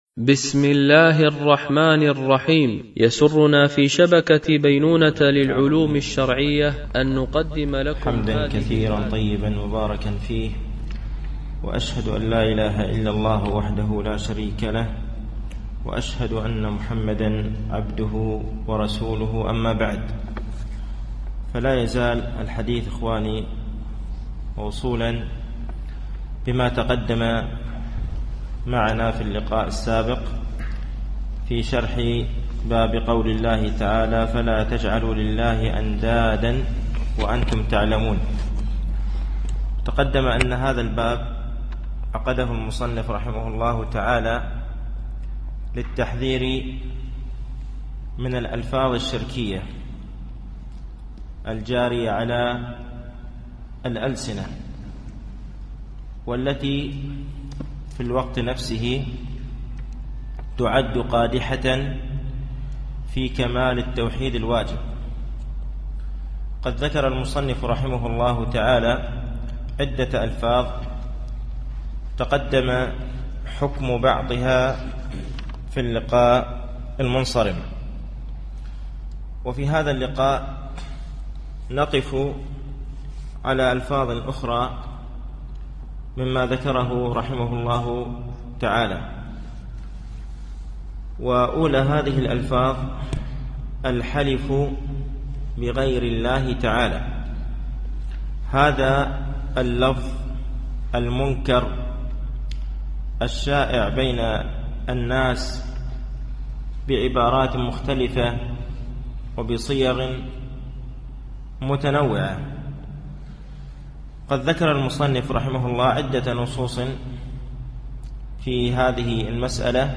التعليق على القول المفيد على كتاب التوحيد ـ الدرس التاسع و العشرون بعد المئة